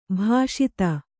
♪ bhāṣita